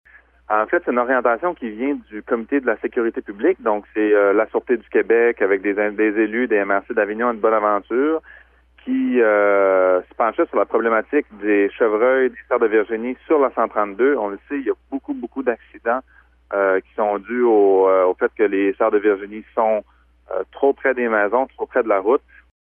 Le préfet de la MRC Avignon, Mathieu Lapointe, explique que les élus ont pris cette décision afin de réduire les collisions avec les chevreuils.